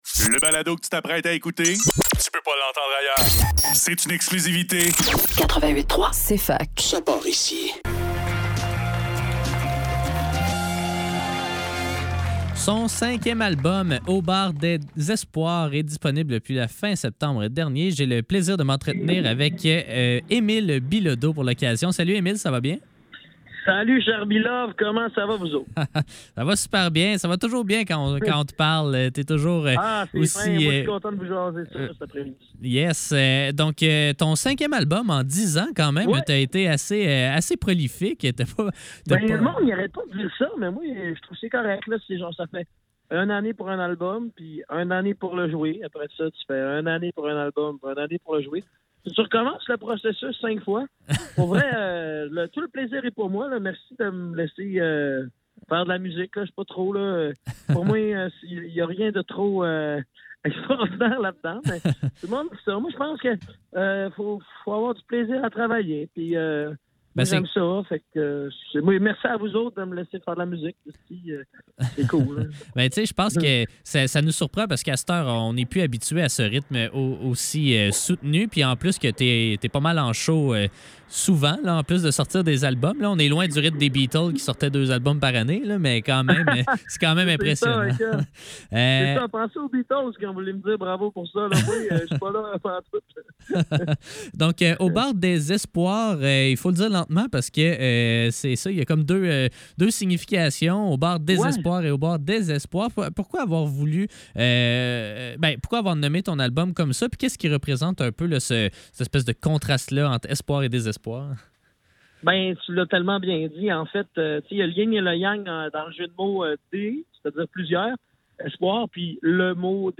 Faudrait que tout l'monde en parle - Entrevue avec Émile Bilodeau - 17 octobre 2023